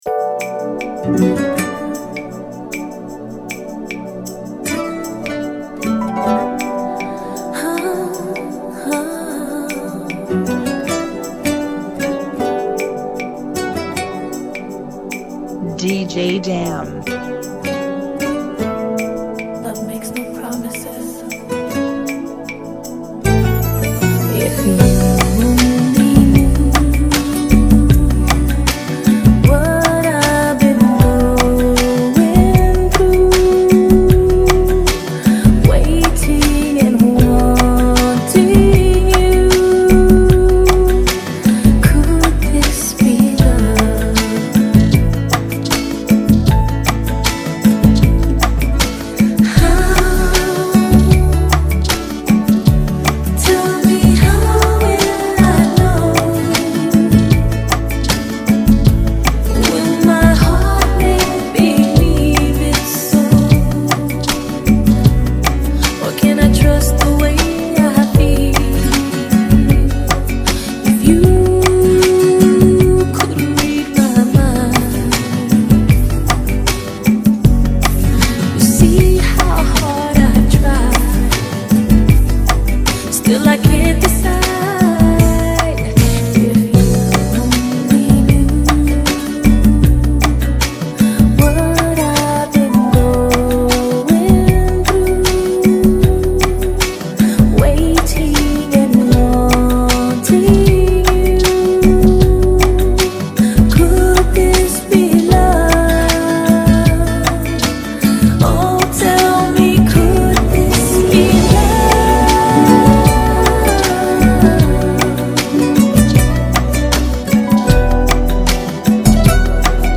155 BPM
Genre: Salsa Remix